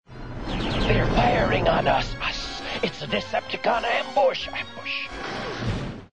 Shrapnel's voice is
entirely different when the Insecticons get fired upon by the Decepticons.